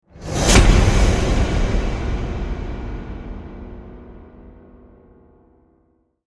Sus-sound-effect.mp3